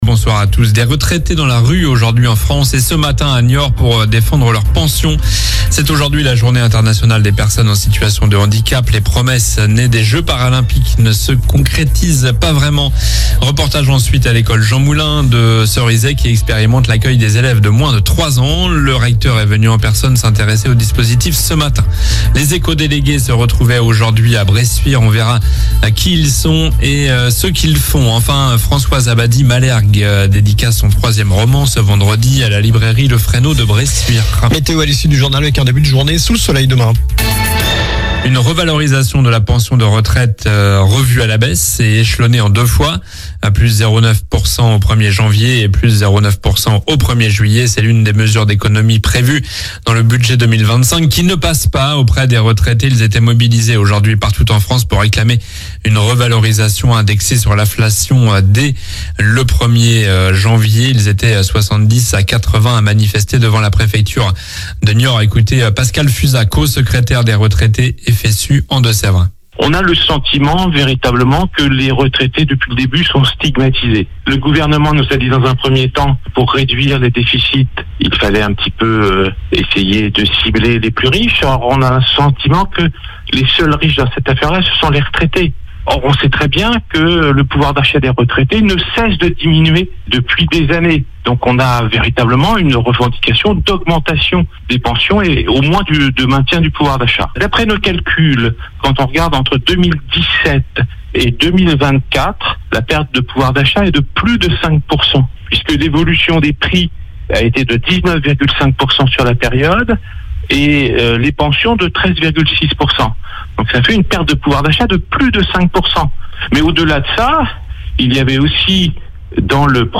Journal du mardi 03 décembre (soir)
- Reportage ensuite à l'école Jean-Moulin de Cerizay qui expérimente l'accueil des élèves de moins de 3 ans.